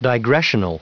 Prononciation du mot digressional en anglais (fichier audio)
Prononciation du mot : digressional